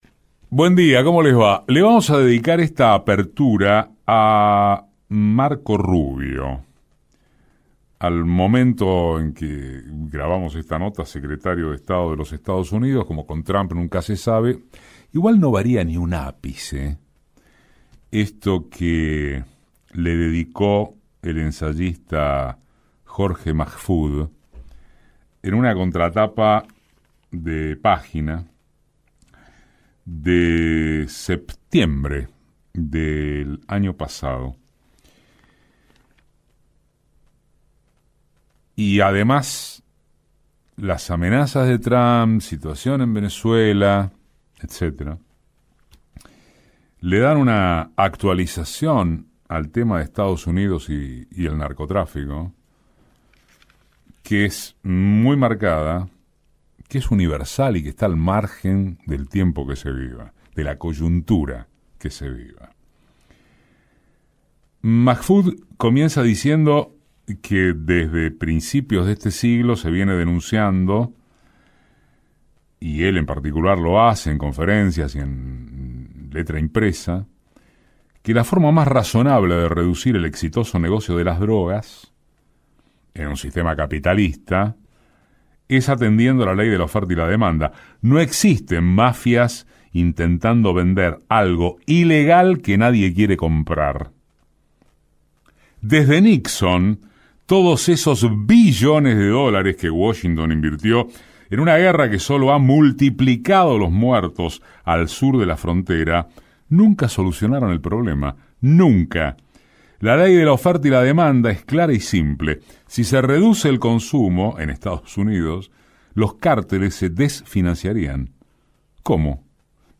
Editorial de Eduardo Aliverti basado en un texto de Jorge Majfud: ‘Marco (Rubio), ¿por qué no sale la luz de los agujeros negros?’